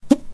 sound_scatter_alt.ogg